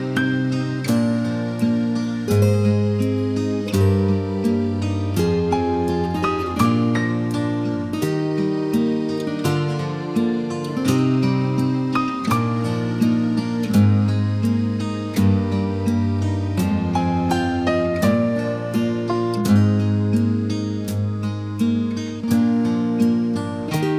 no Backing Vocals Country (Male) 3:10 Buy £1.50